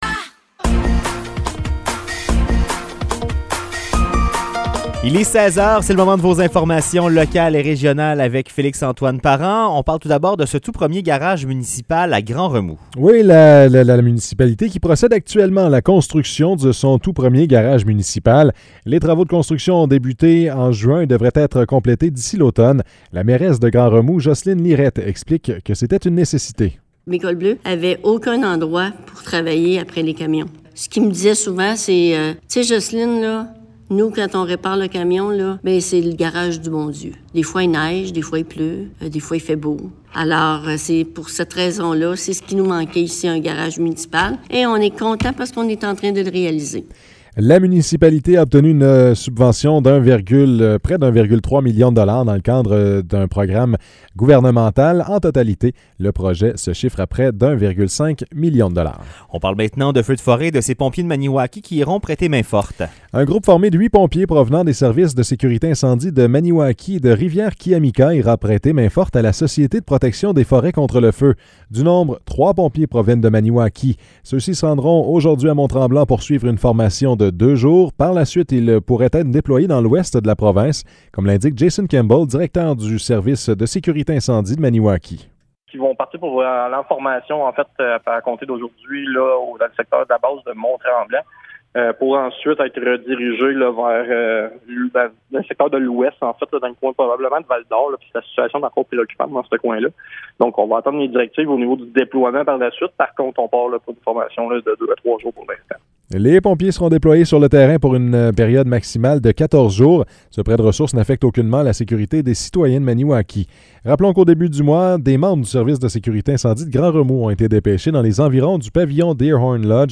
Nouvelles locales - 12 juin 2023 - 16 h